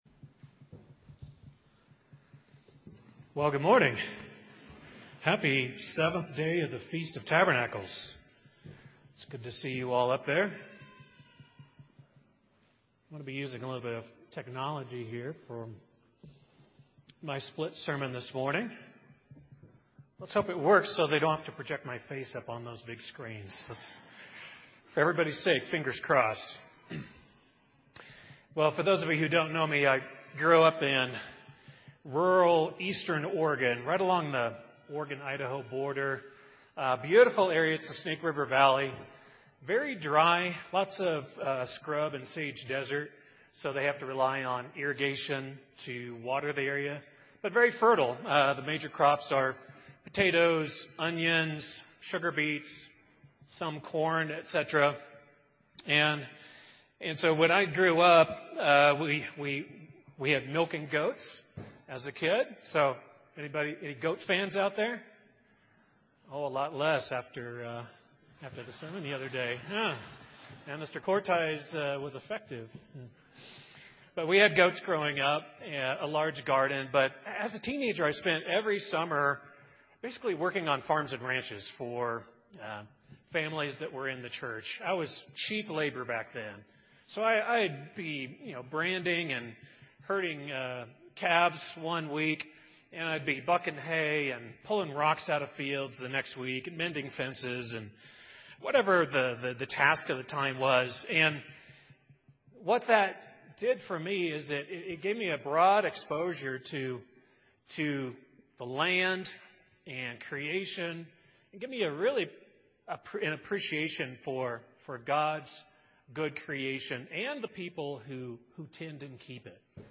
This sermon was given at the Estes Park, Colorado 2022 Feast site.